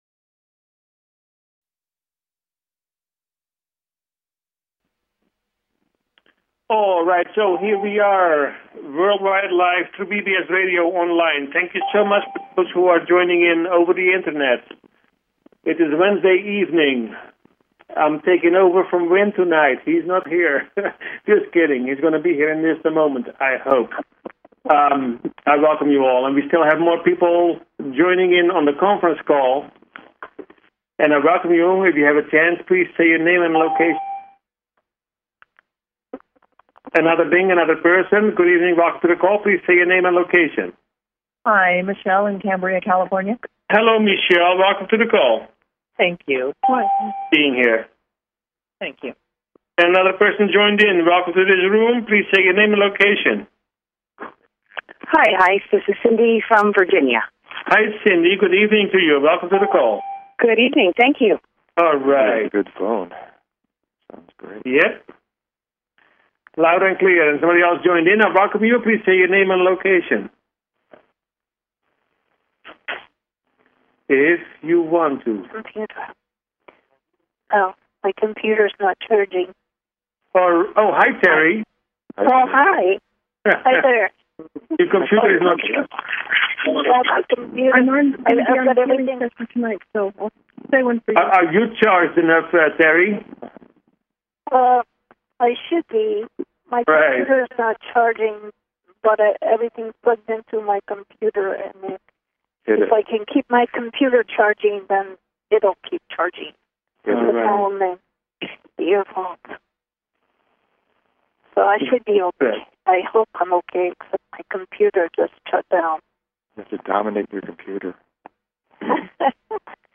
Talk Show Episode, Audio Podcast, You_Got_Questions_We_Got_Answers and Courtesy of BBS Radio on , show guests , about , categorized as